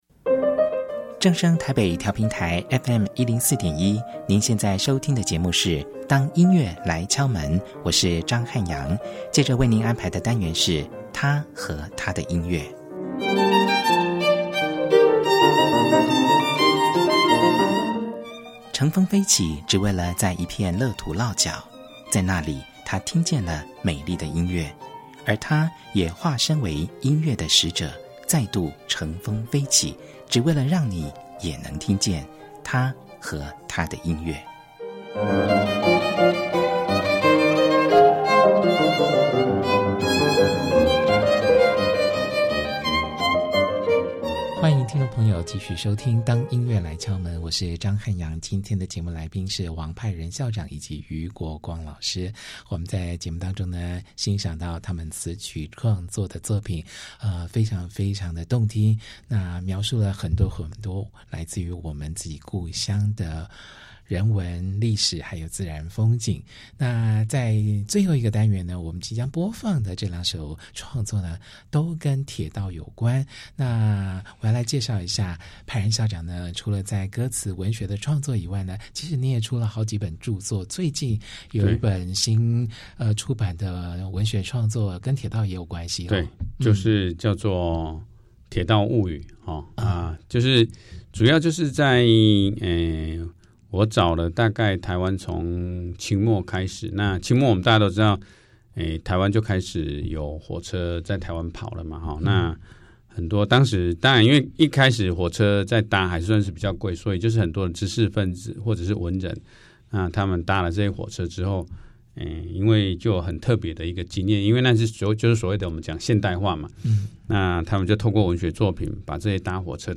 訪問